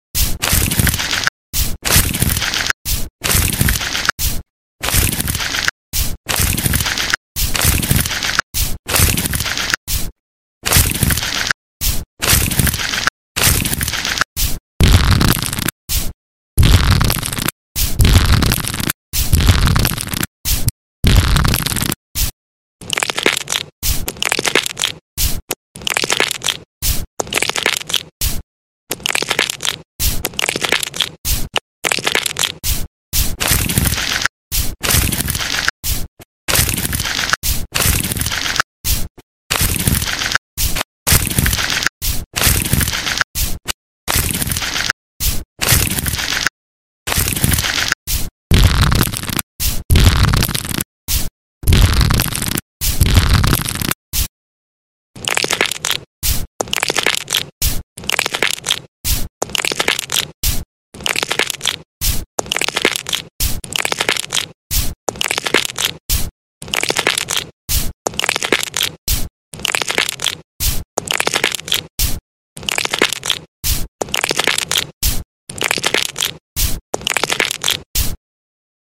ASMR 2D relaxing foot pampering sound effects free download
ASMR relief street with foot massage